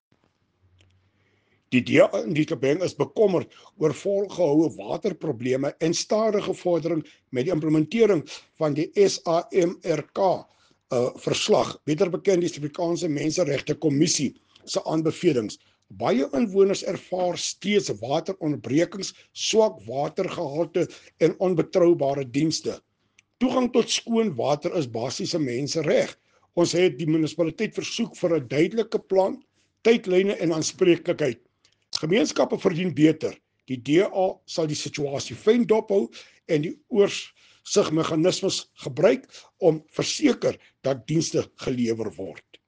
Afrikaans soundbites by Cllr Hilton Maasdorp and